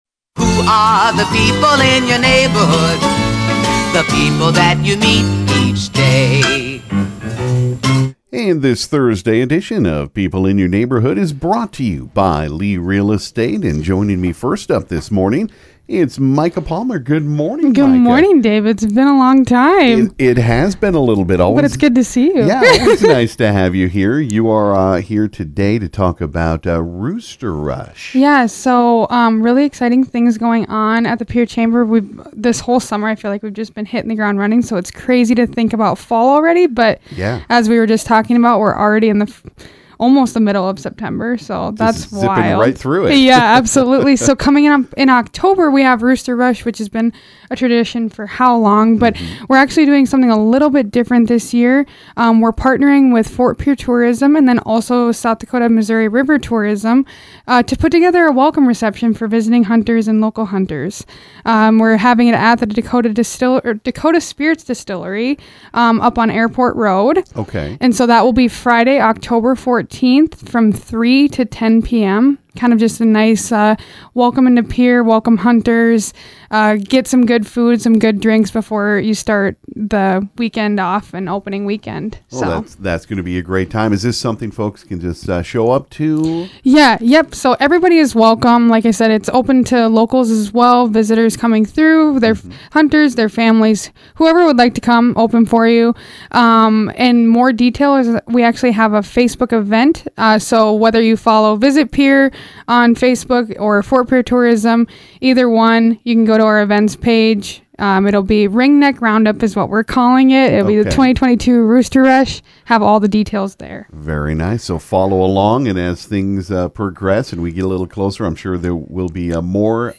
Also in studio this morning was Ft. Pierre Mayor Gloria Hanson. She talked about the proposed Ambulance District that now will not be happening as the towns and counties are going back to the drawing board to keep coverage in the area. She also talked about the Indian National Relays coming to the Stanley County Fairgrounds the last weekend of this month.